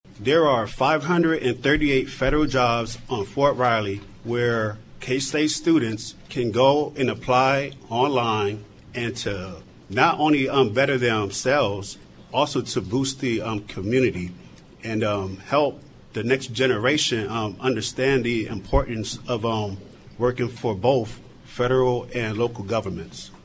During this past weekend’s League of Women Voters Forum on KMAN, candidates were asked what the city’s role should be in spurring development of jobs that provide a livable wage.
Seven took part in the forum Saturday at the Manhattan Public Library, hosted by the League and co-sponsored by the local chapter of the American Association of University Women and the Manhattan Area Chamber of Commerce.